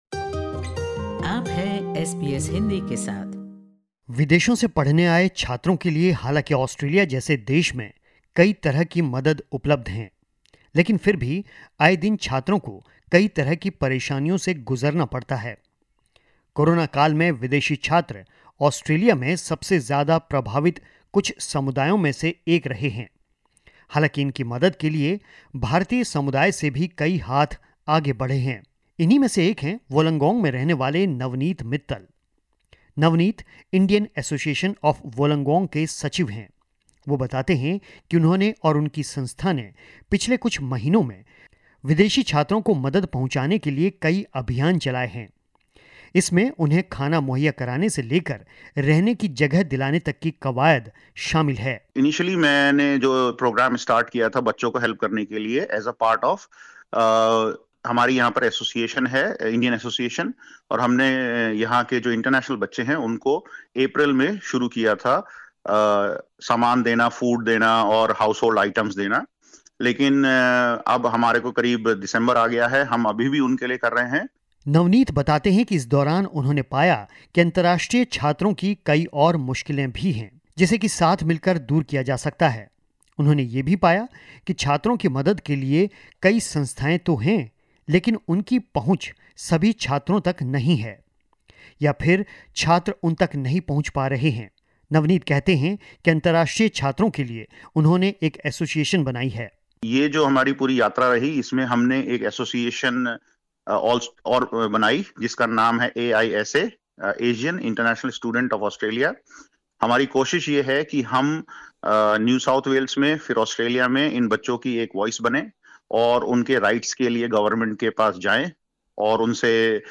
सुनिए, उनसे यह खास बातचीतः